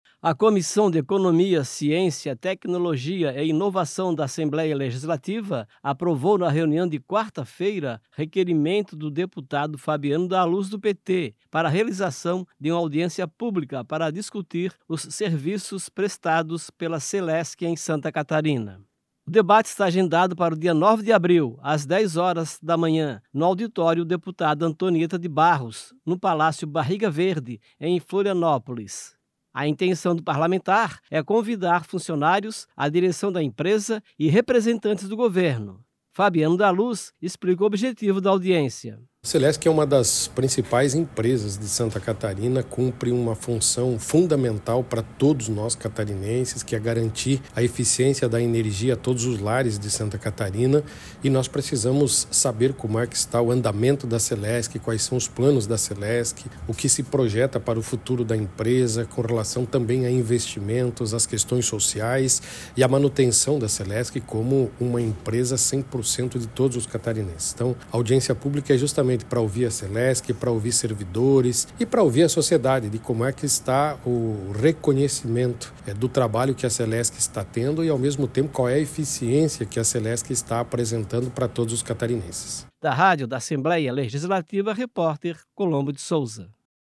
Eentrevisa com:
- deputado Fabiano da Luz (PT).